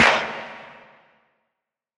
Clp (TI$M).wav